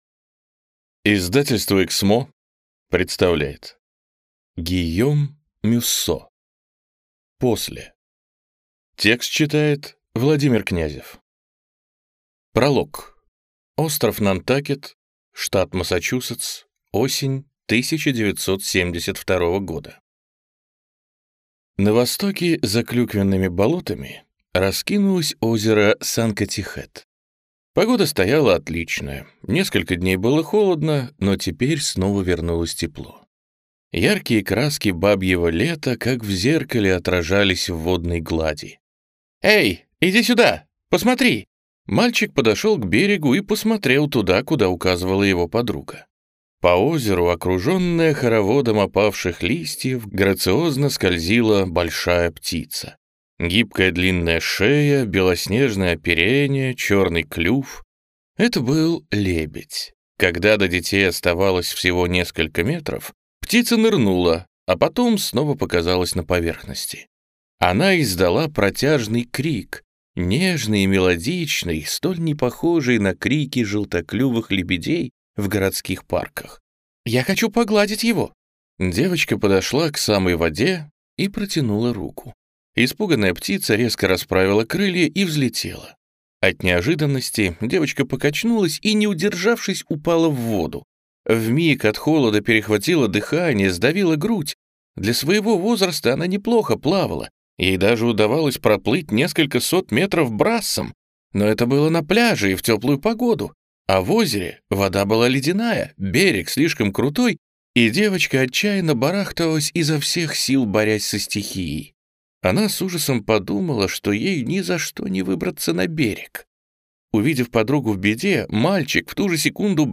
Аудиокнига После…